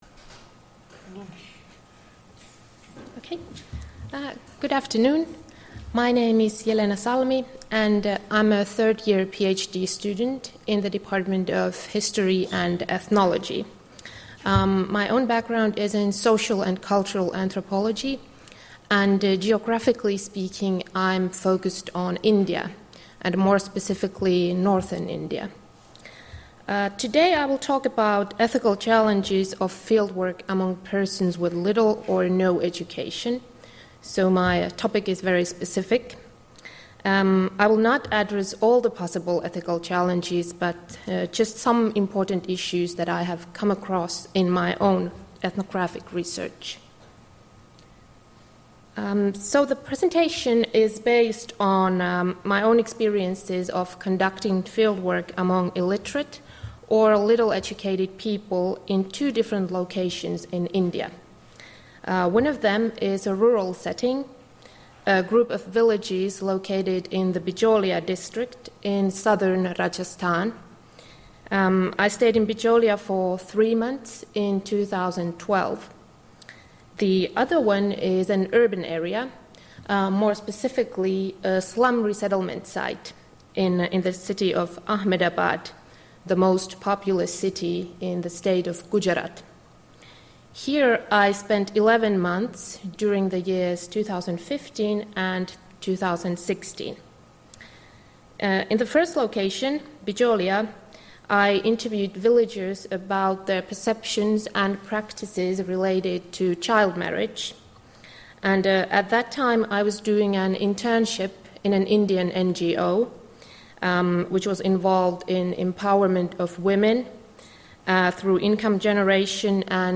Lecture 17.11.2016 - Part 2 — Moniviestin